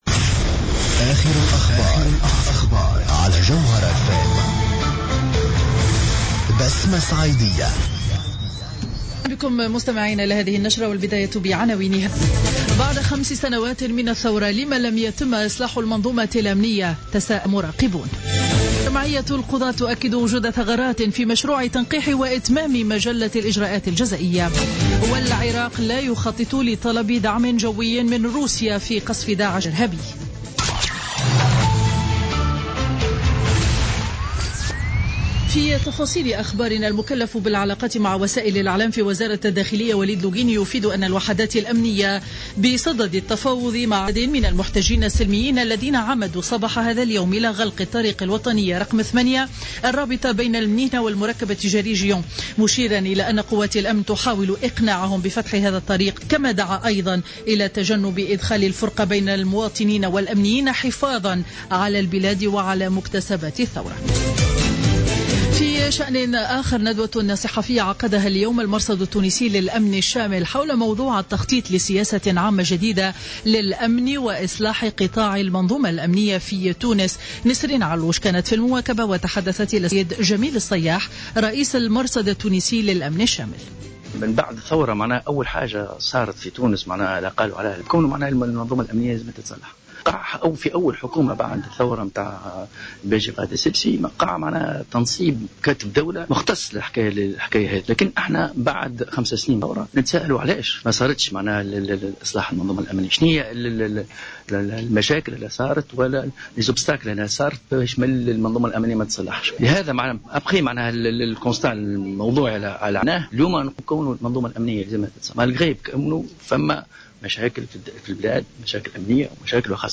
نشرة أخبار منتصف النهار ليوم الجمعة 22 جانفي 2016